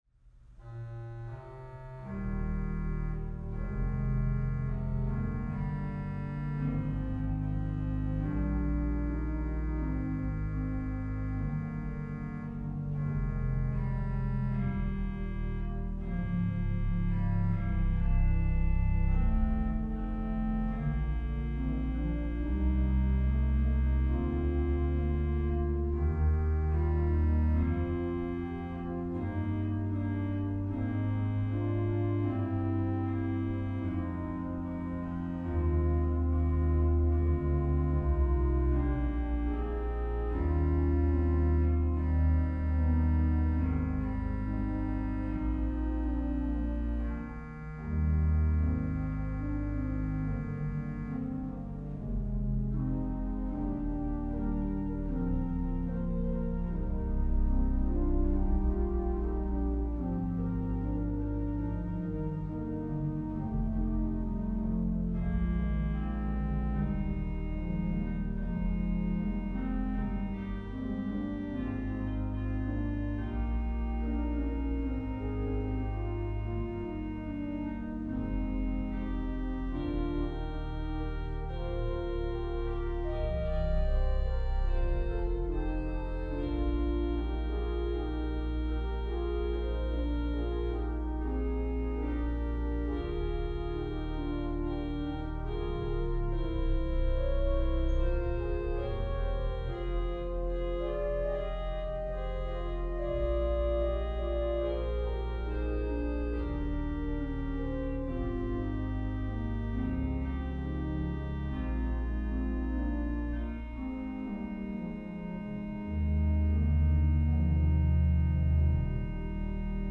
Voicing: Organ